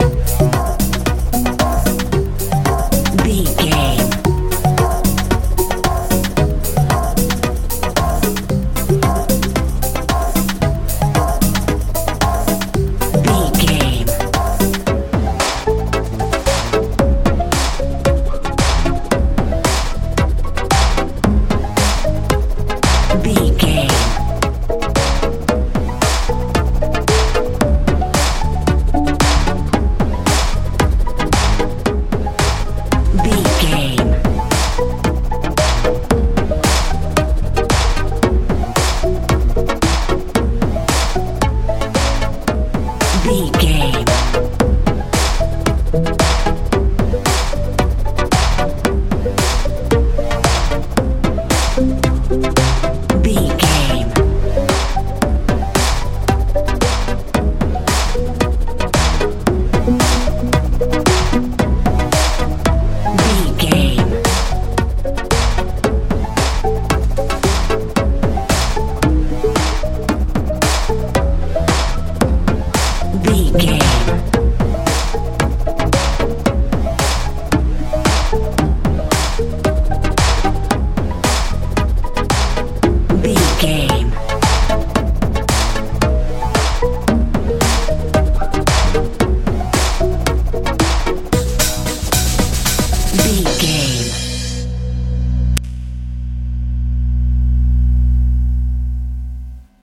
modern dance feel
Ionian/Major
magical
strange
synthesiser
bass guitar
drums
80s
90s